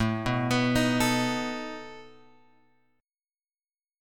A Suspended 2nd Flat 5th